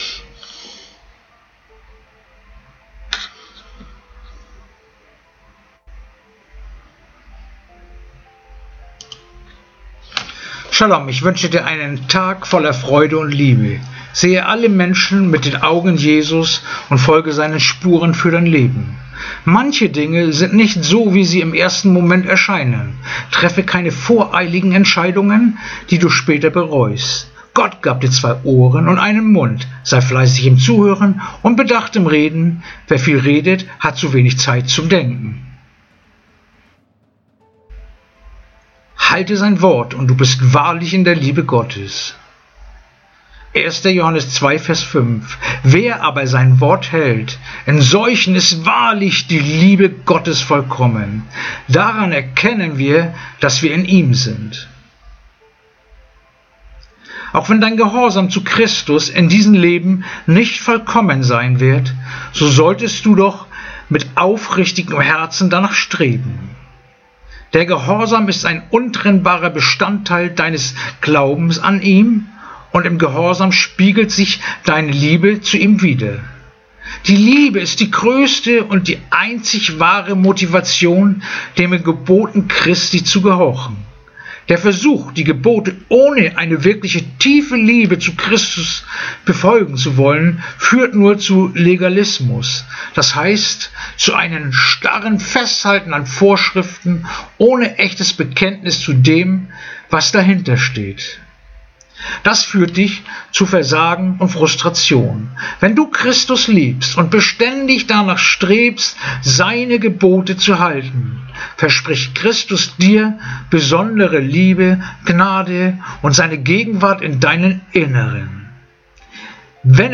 Andacht-vom-15-April-1-Johannes-2-5